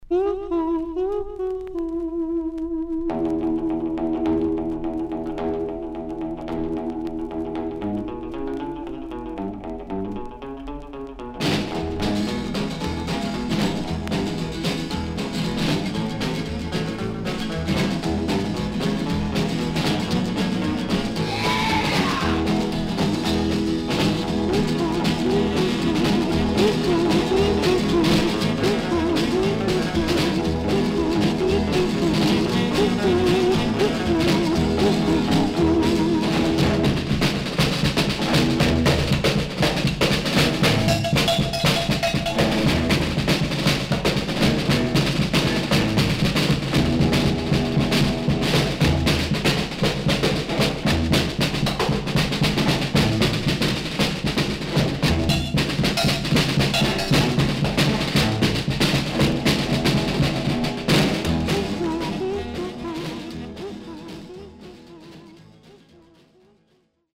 少々軽いパチノイズの箇所あり。全体に少々サーフィス・ノイズがあります。